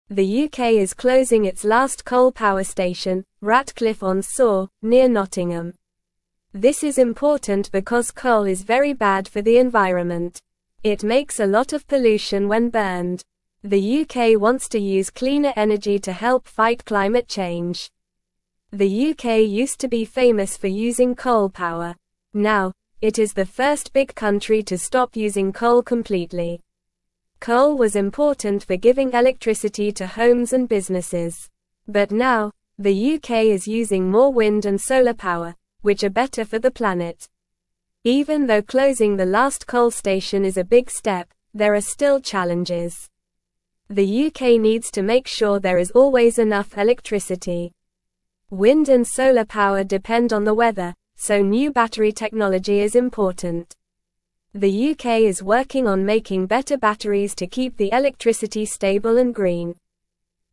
Normal
English-Newsroom-Lower-Intermediate-NORMAL-Reading-UK-Stops-Using-Coal-for-Electricity-Fights-Climate-Change.mp3